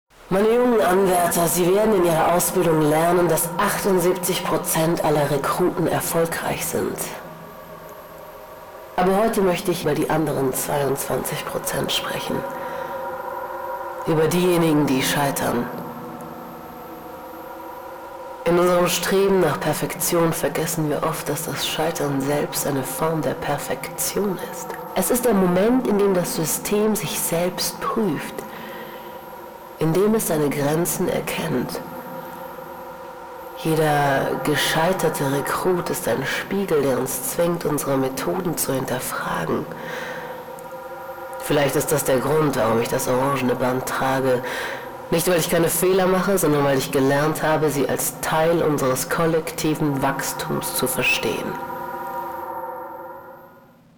Begleitet von binauralem Sounddesign erleben Sie ein akustisches Abenteuer, das Sie mitten in die Inszenierung zieht.